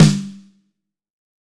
Index of /90_sSampleCDs/AKAI S6000 CD-ROM - Volume 3/Drum_Kit/ROCK_KIT1